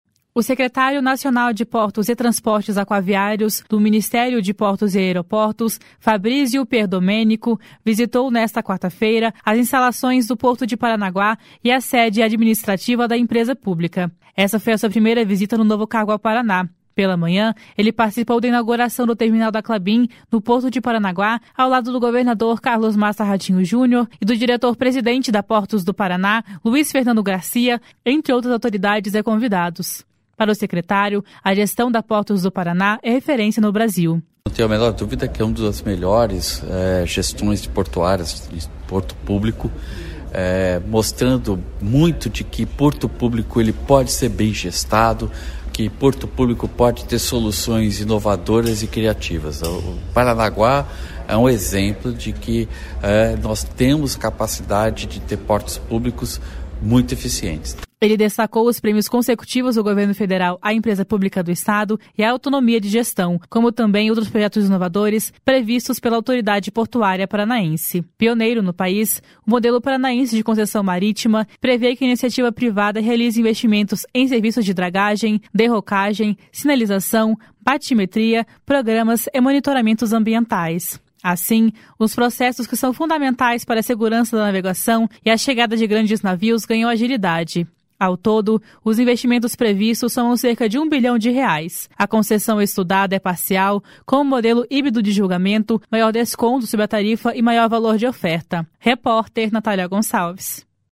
Para o secretário, a gestão da Portos do Paraná é referência no Brasil. // SONORA FABRIZIO PIERDOMENICO //